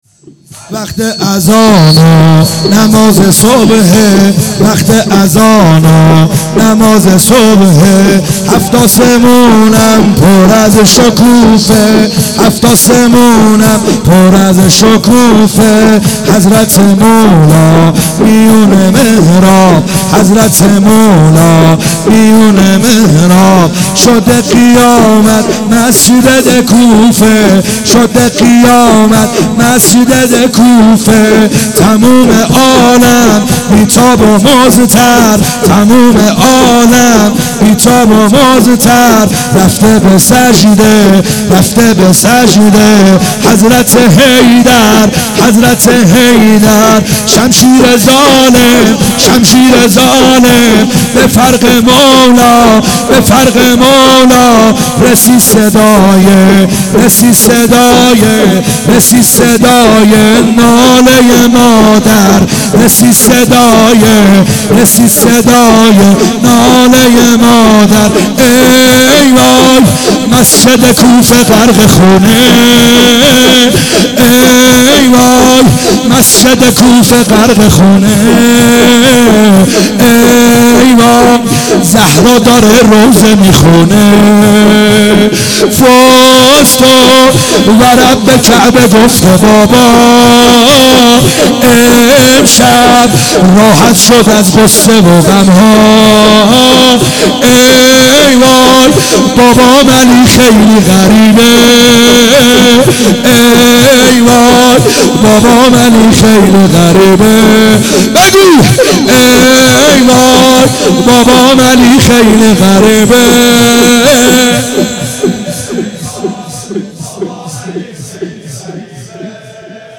خیمه گاه - بیرق معظم محبین حضرت صاحب الزمان(عج) - لطمه زنی ا وقت اذان و نماز صبح